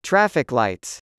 14. traffic lights /ˈtræf.ɪk laɪt/: đèn giao thông